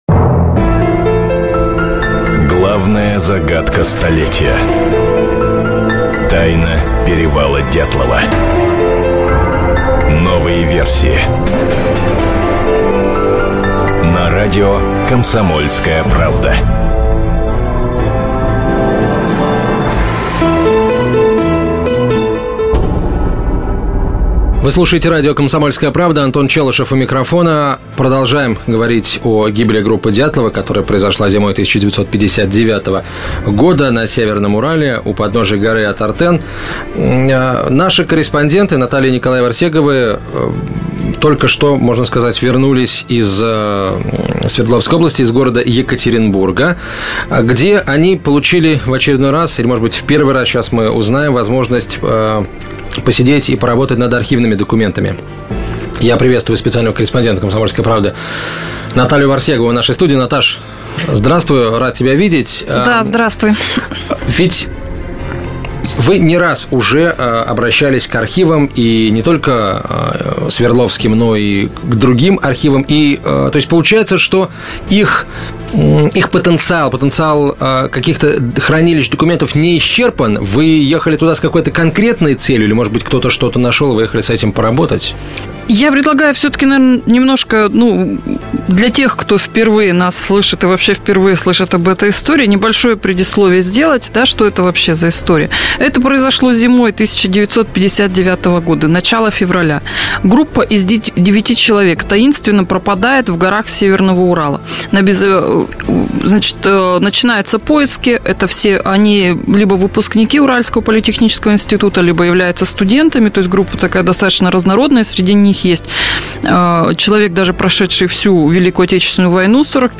Аудиокнига Продолжение расследования: новые документы Свердловского архива | Библиотека аудиокниг